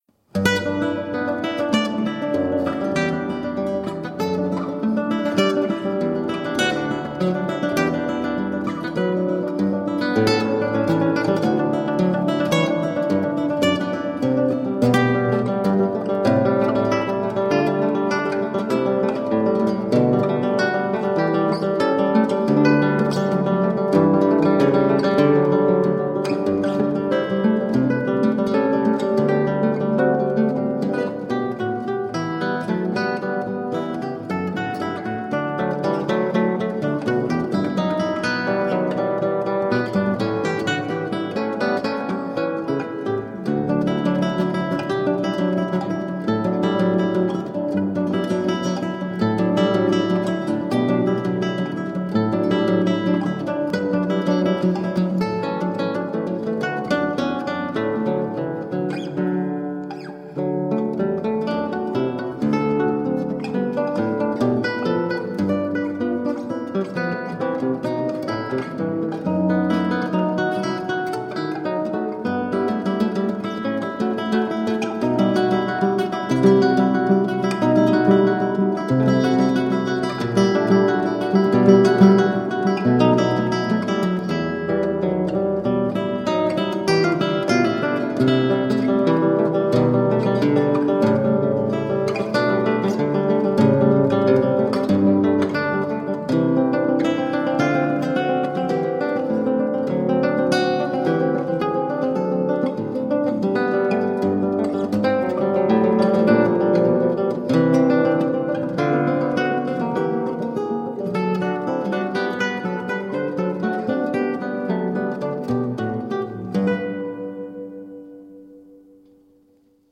Elegant classical guitar